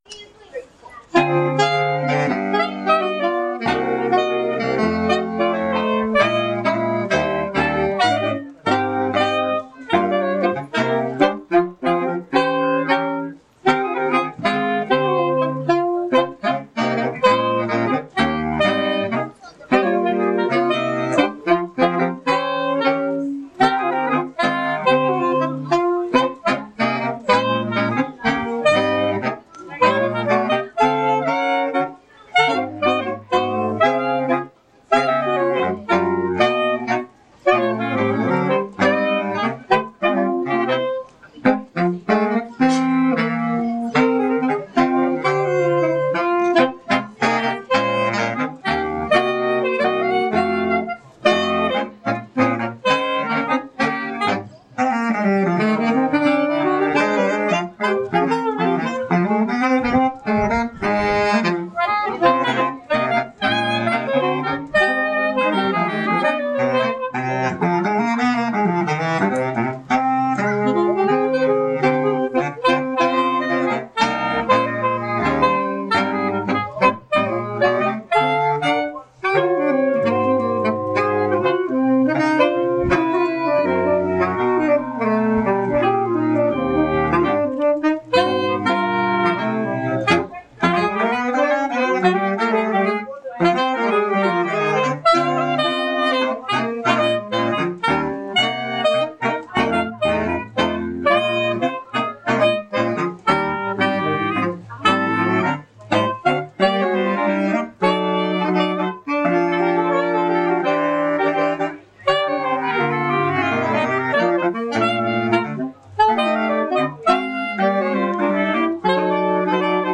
The following are live outdoor recordings of the Niehaus Jazz Mosaics Suite, when we played at the Birmingham Botanical Gardens in 2017.
Soprano
Alto
Tenor
Baritone